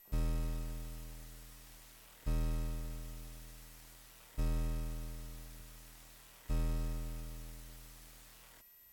The combination of low frequency and the triangle wave makes very evident the presence of aliasing in some of them.
test-fpgasid-8580-dac-crunch.mp3